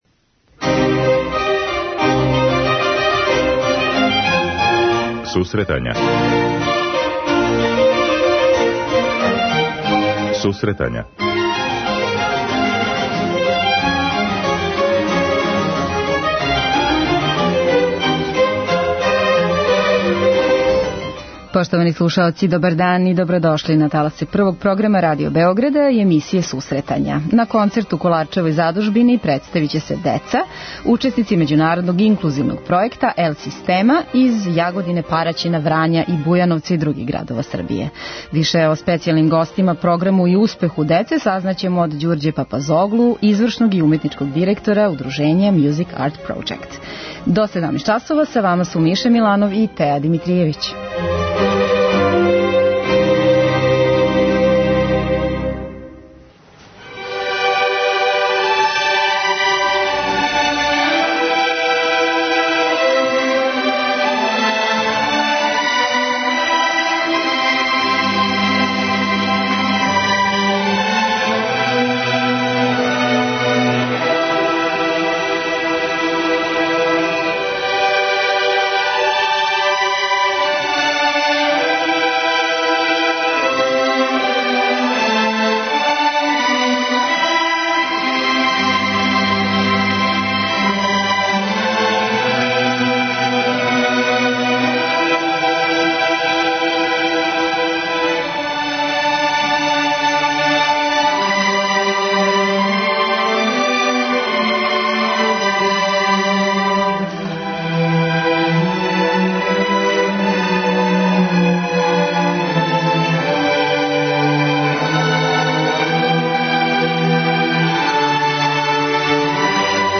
преузми : 10.66 MB Сусретања Autor: Музичка редакција Емисија за оне који воле уметничку музику.